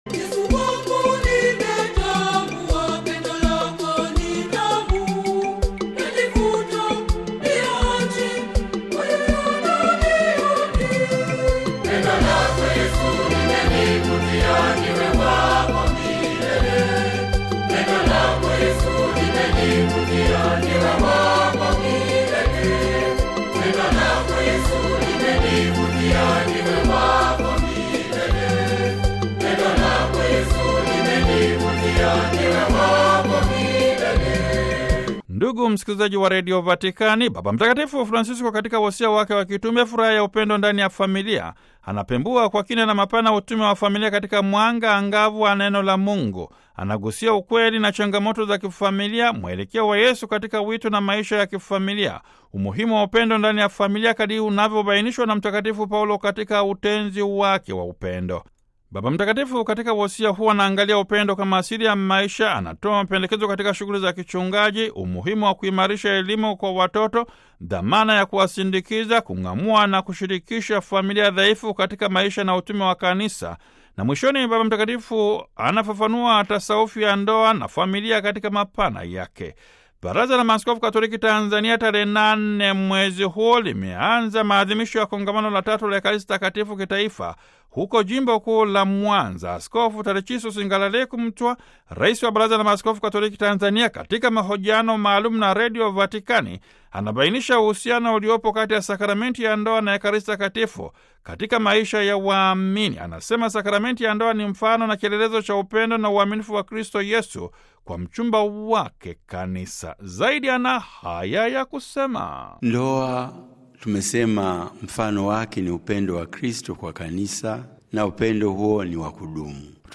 Askofu Tarcisius Ngalalekumtwa, Rais wa Baraza la Maaskofu Katoliki Tanzania katika mahojiano maalum na Radio Vatican anabainisha uhusiano uliopo kati ya Sakramenti ya Ndoa na Ekaristi Takatifu katika maisha ya waamini.